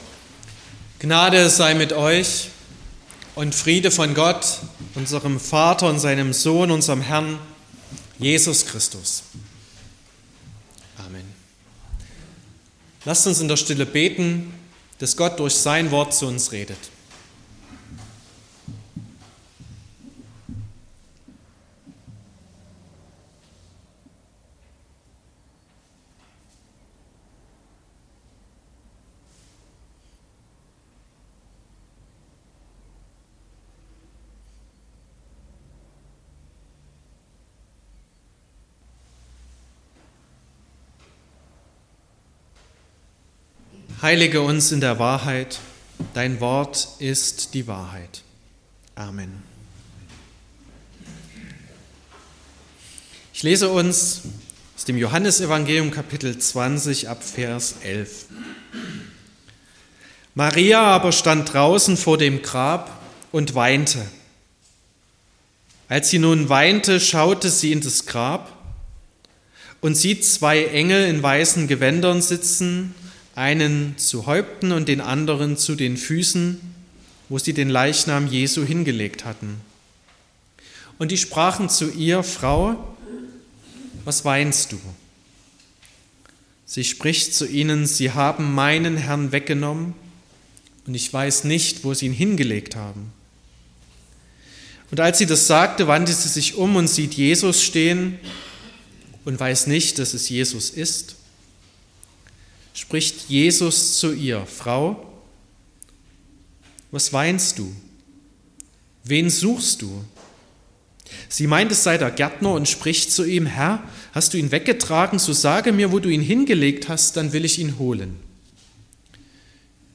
20.04.2025 – Gottesdienst